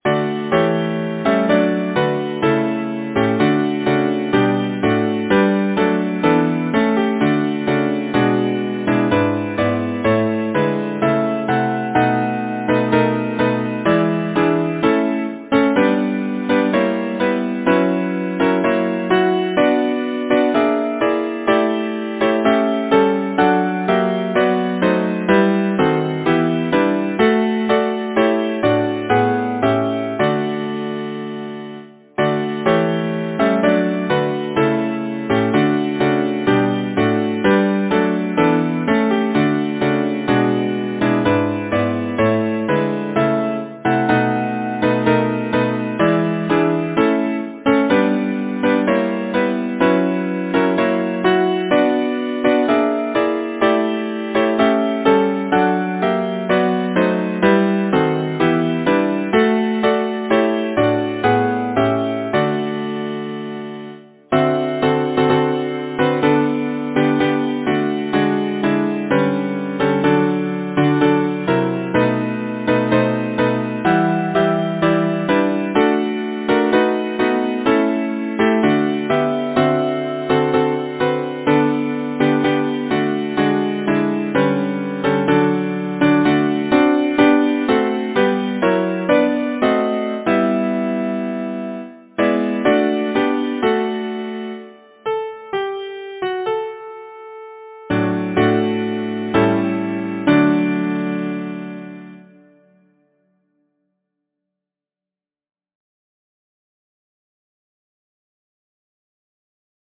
Title: It is not always May Composer: John Francis Barnett Lyricist: Henry Wadsworth Longfellow Number of voices: 4vv Voicing: SATB Genre: Secular, Partsong
Language: English Instruments: A cappella